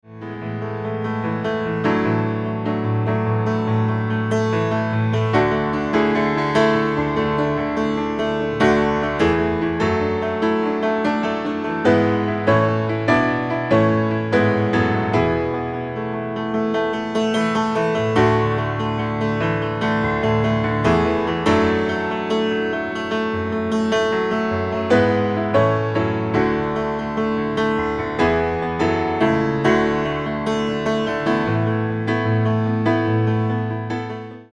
a series of instrumental recordings